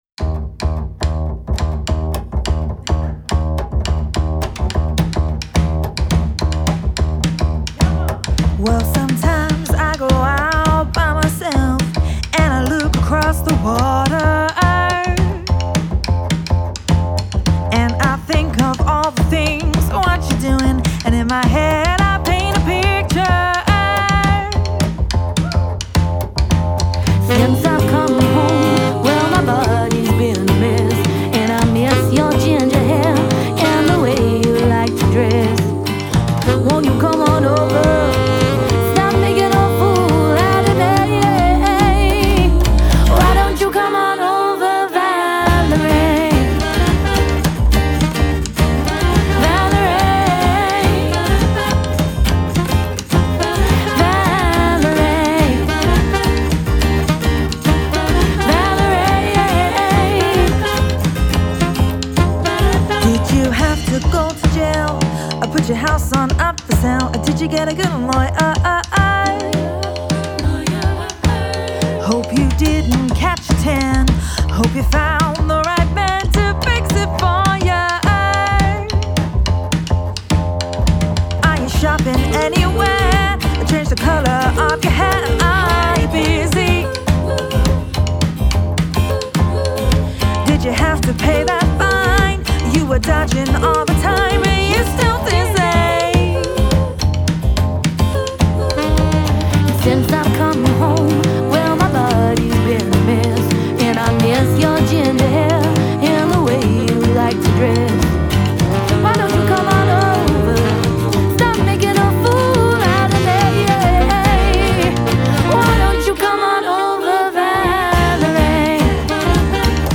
all-female acoustic roaming music act!
• 3-Piece: Female Vocals, Sax & Guitar
• Female Vocals
• Sax
• Guitar